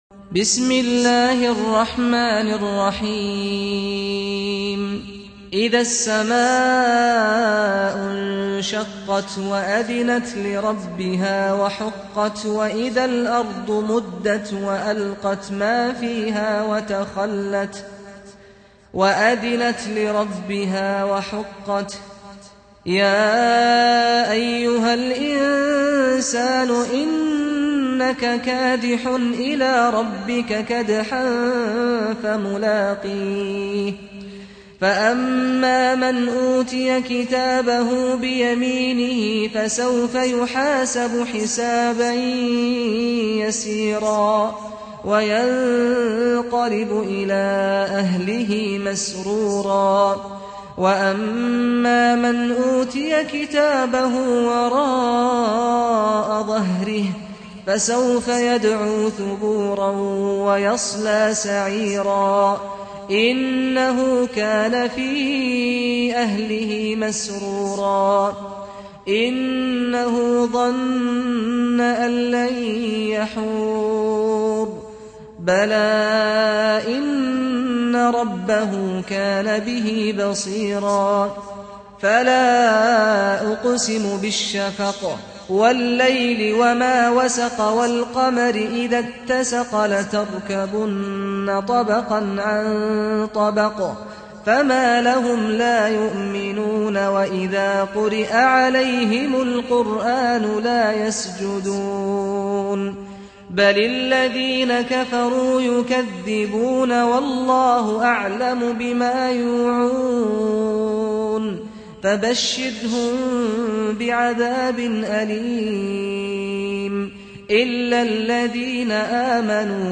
سُورَةُ الانشِقَاقِ بصوت الشيخ سعد الغامدي